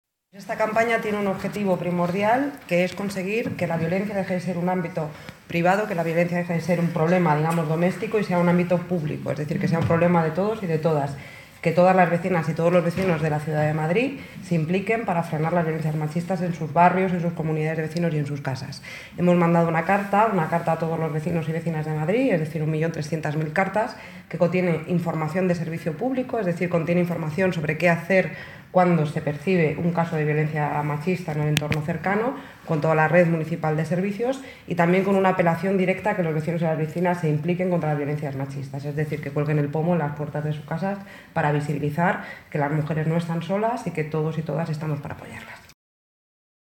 La delegada de Políticas de Género y Diversidad, Celia Mayer, comenta la implicación de los vecinos y vecinas en la lucha contra la violencia machista